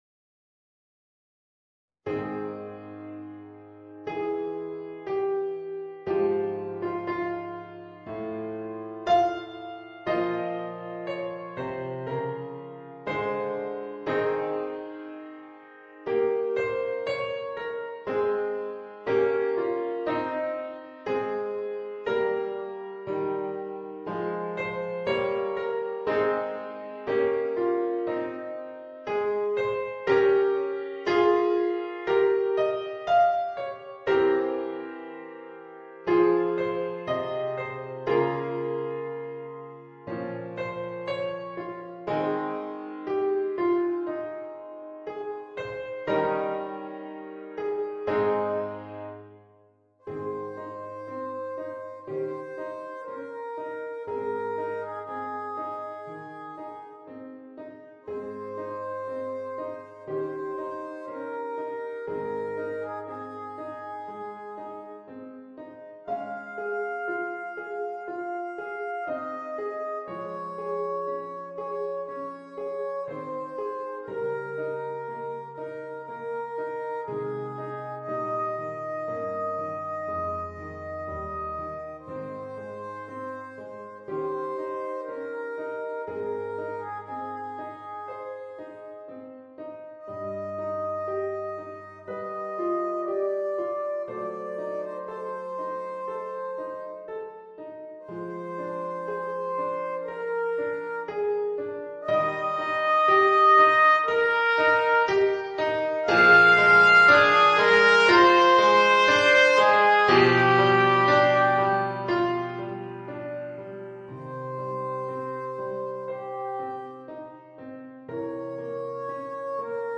für Trompete und Klavier